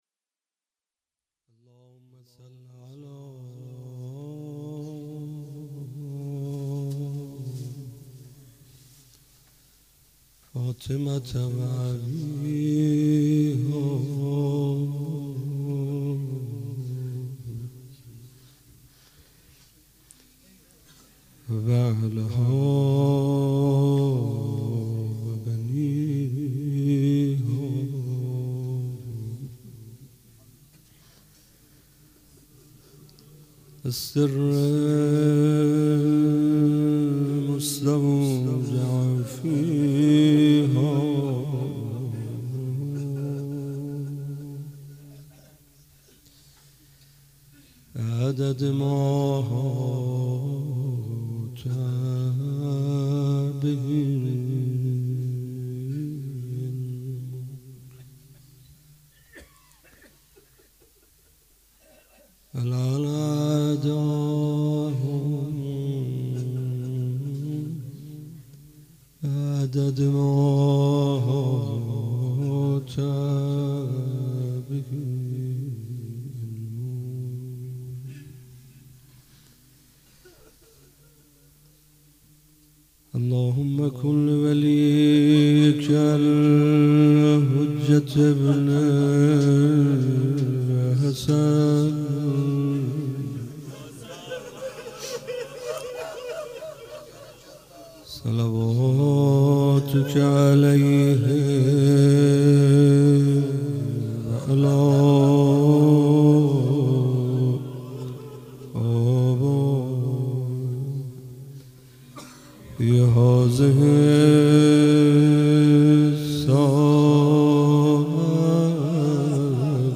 فاطمیه 95 - شب اول - روضه